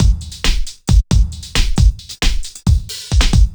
drums01.wav